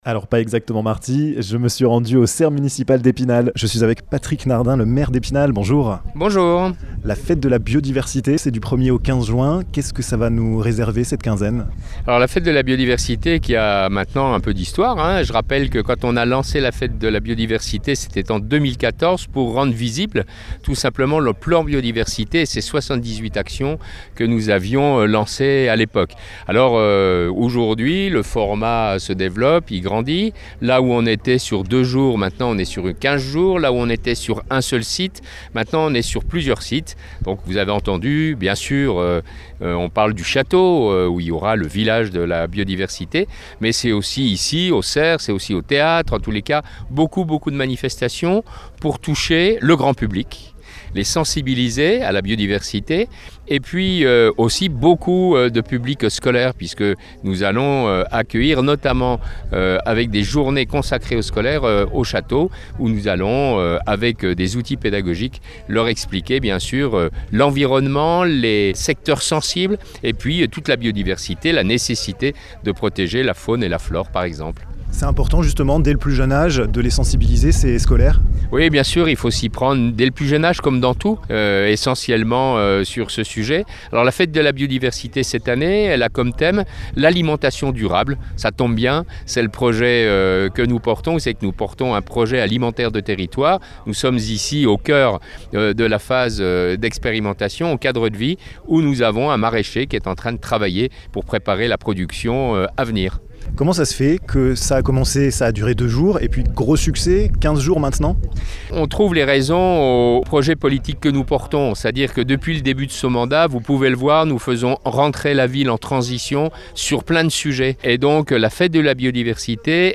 La fête de la biodiversité c'est jusqu'au 15 juin à Epinal! Patrick Nardin, le maire, a accepté de répondre à nos questions